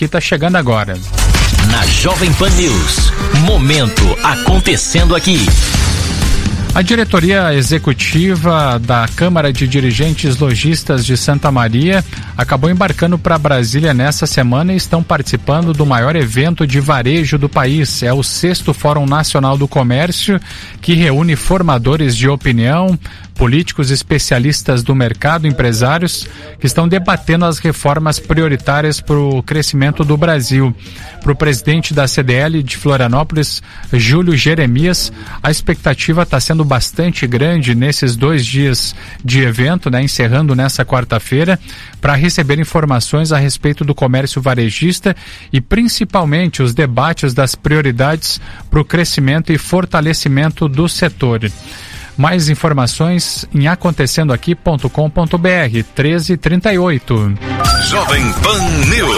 Rádio: Jovem Pan News/Florianópolis